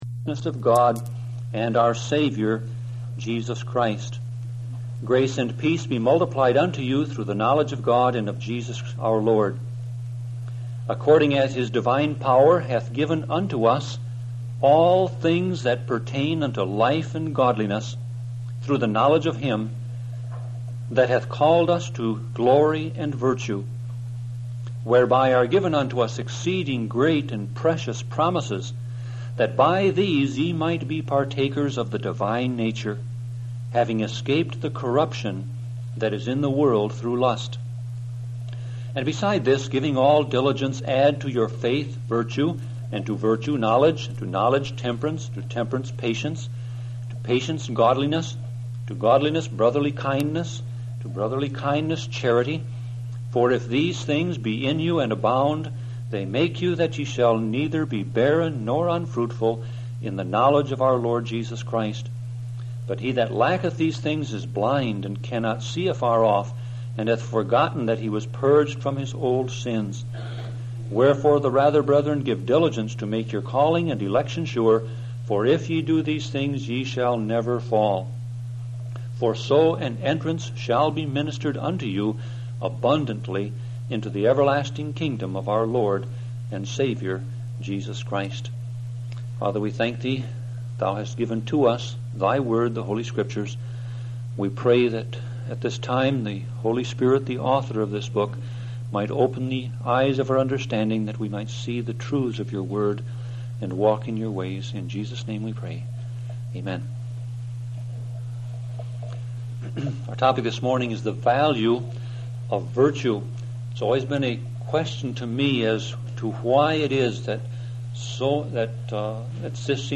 Sermon Audio Passage: 2 Peter 1:1-11 Service Type